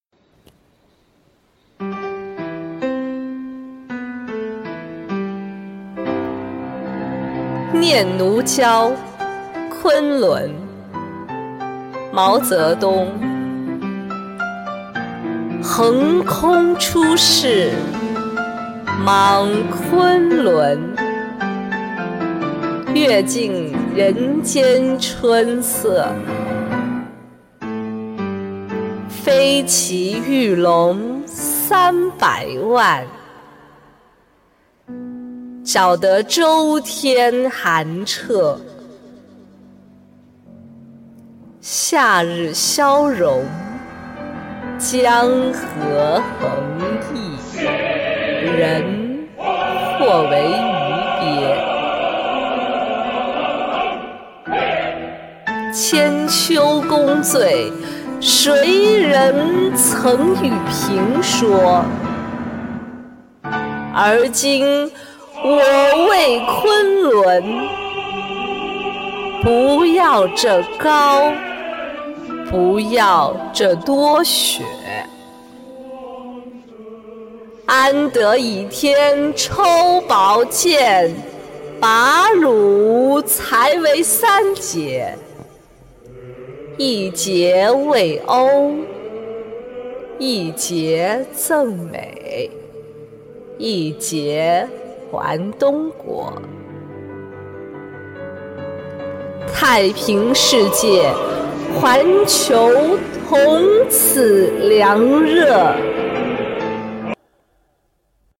为创新开展党史学习教育，福建省女子监狱成立青年读诗班，通过读诗学史的形式，带领广大党员民警在诗词学习中，领悟毛泽东思想哲理，生动展现中国革命、中共党史的发展进程。